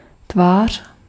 Ääntäminen
Synonyymit genre Ääntäminen France: IPA: /as.pɛ/ Haettu sana löytyi näillä lähdekielillä: ranska Käännös Ääninäyte Substantiivit 1. aspekt 2. tvář {f} Muut/tuntemattomat 3. stránka {f} Suku: m .